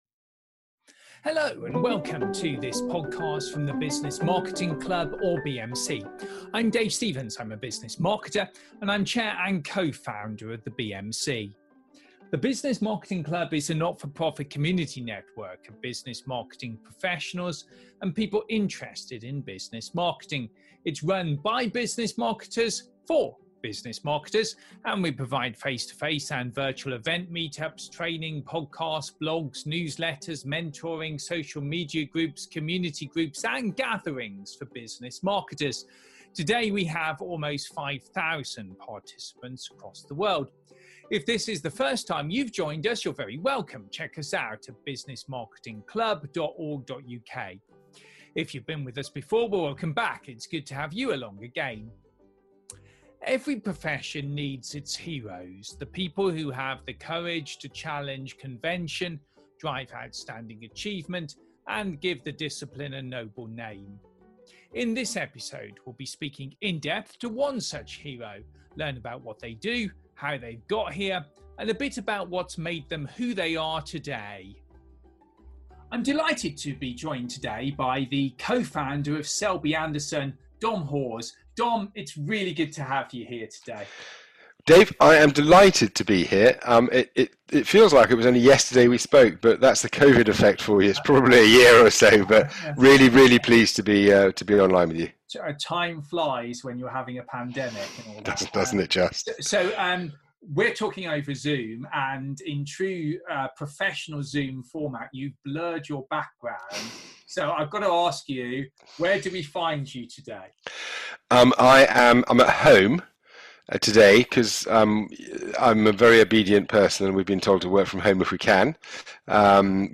Continuing a series of in-depth interviews with some of Business Marketing’s heroes.